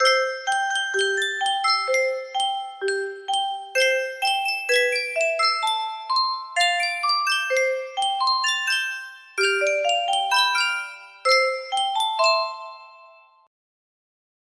Sankyo Music Box - This Little Light of Mine KFS music box melody
Full range 60